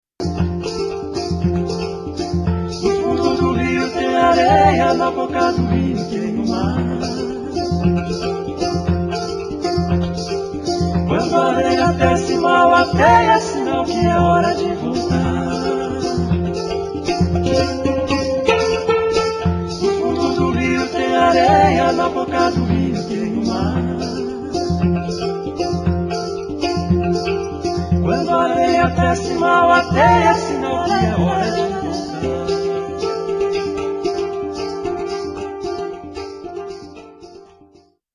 con una bellissima linea melodica dell'hang